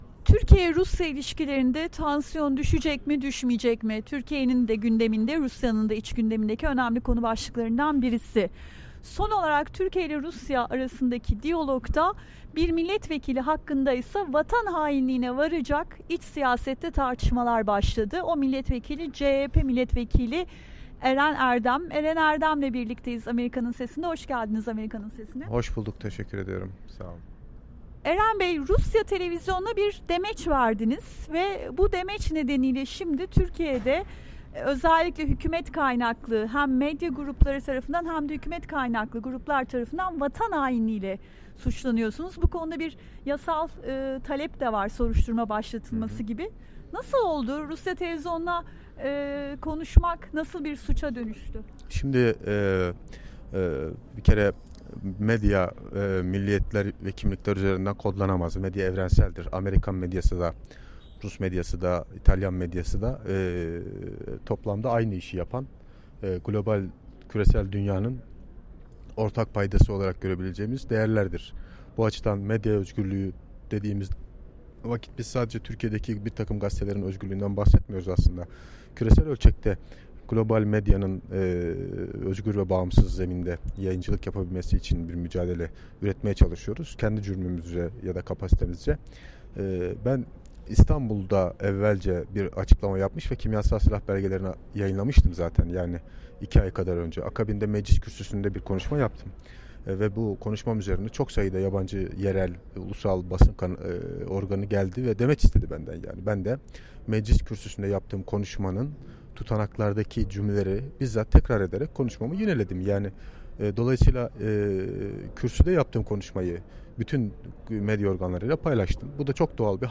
CHP Milletvekili Eren Erdem'le söyleşi